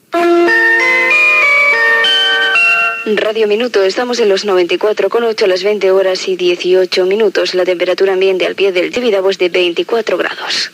Identificació, hora i temperatura
Banda FM